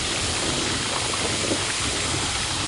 poison_loop_01.ogg